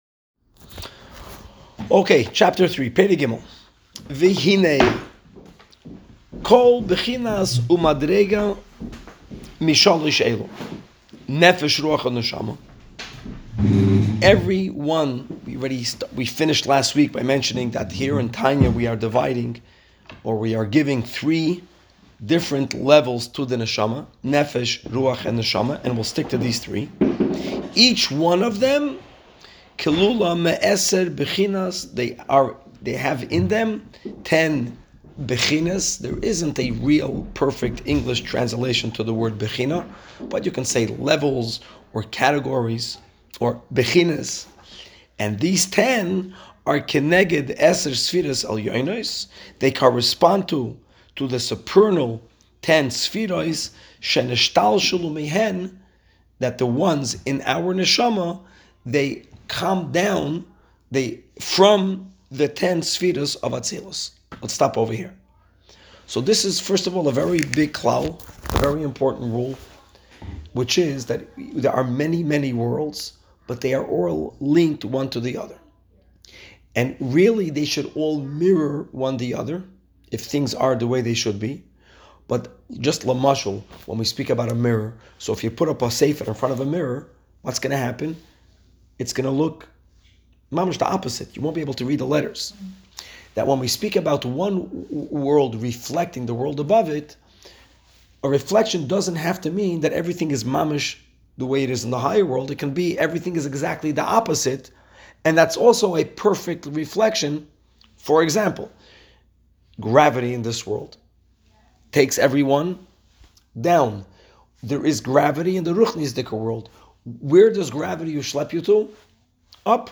Women's Shiur
Woman's Class